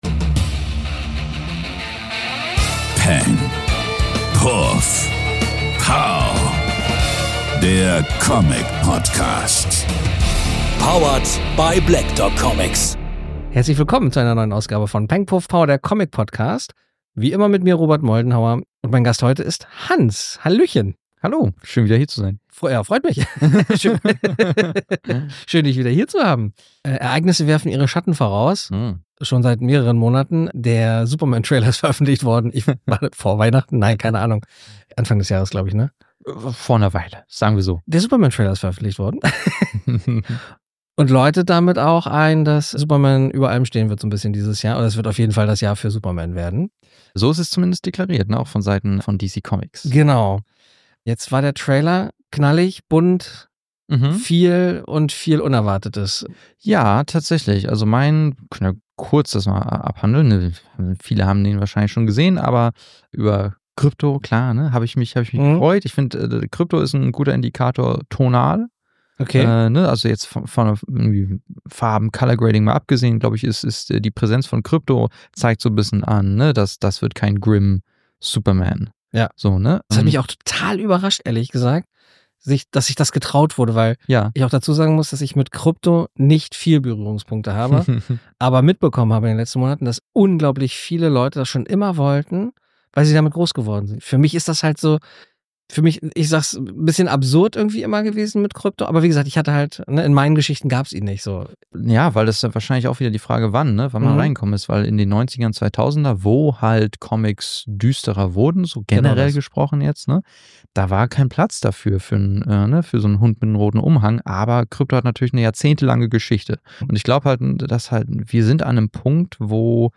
Der eine Sammler, der andere Händler, quatschen die beiden vorrangig über englischsprachige Comics, deren Adaptionen für Kino und Fernsehen, die Verlage dahinter sowie die Autoren und Zeichner.